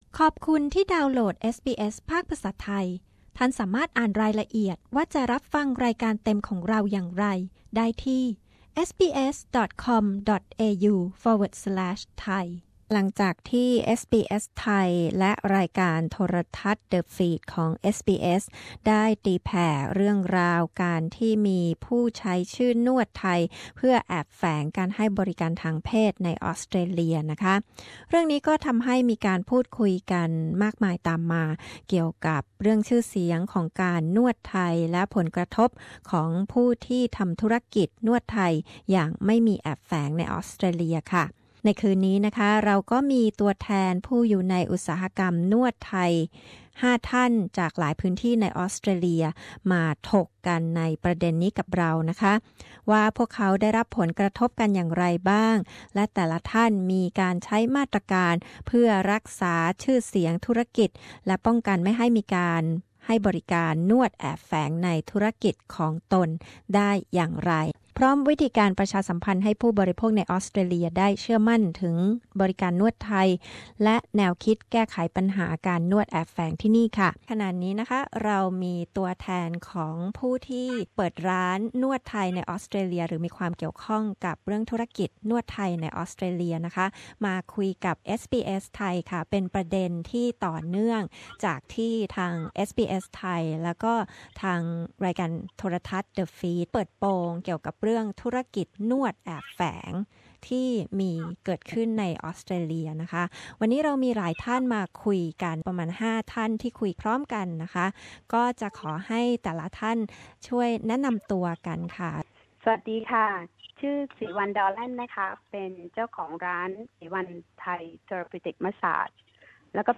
ตัวแทนจากอุตสาหกรรมนวดไทยในออสเตรเลีย ถกประเด็นผลกระทบจากนวดแอบแฝง มาตรการรักษาชื่อเสียงและป้องกันไม่ให้มีการนวดแอบแฝงในธุรกิจของตน การประชาสัมพันธ์ให้ผู้บริโภคเชื่อมั่น และแนวคิดแก้ปัญหานวดแอบแฝง